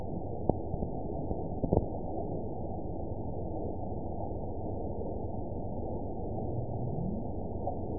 event 922658 date 02/26/25 time 23:04:34 GMT (2 months ago) score 8.09 location TSS-AB01 detected by nrw target species NRW annotations +NRW Spectrogram: Frequency (kHz) vs. Time (s) audio not available .wav